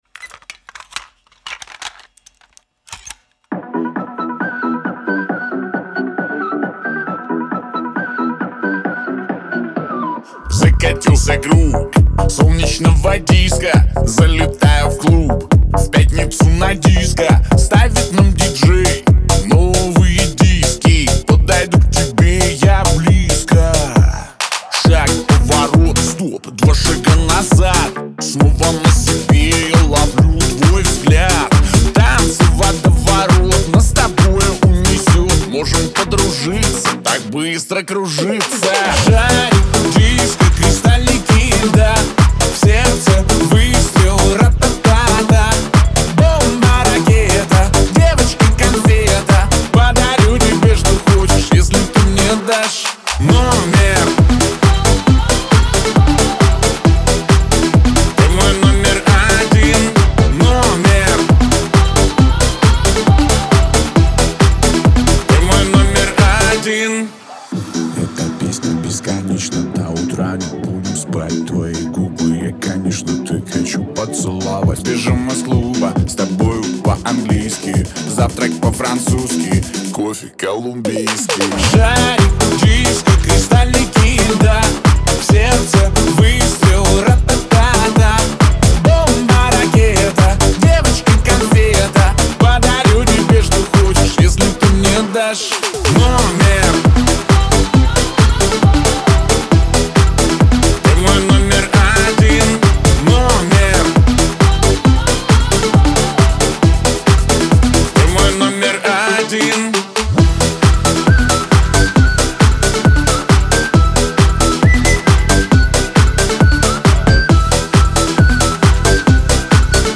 энергичная композиция в жанре поп-рэп